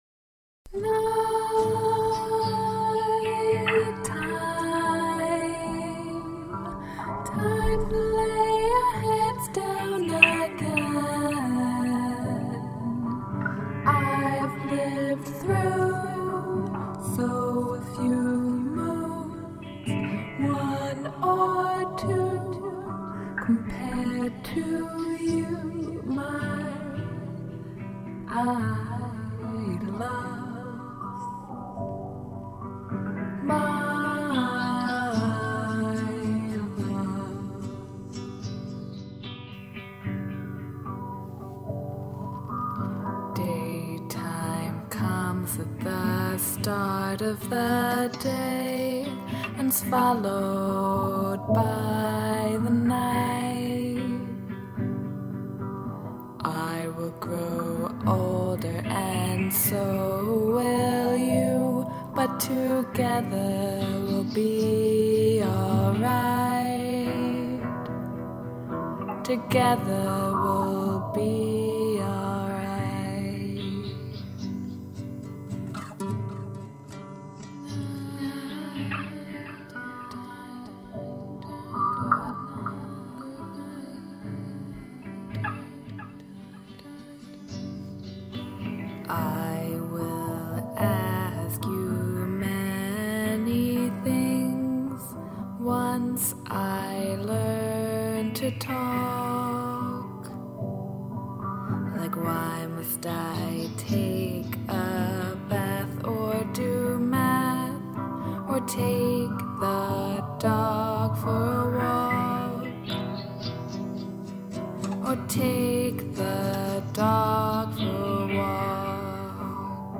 Lovely voice! Rad flange effects!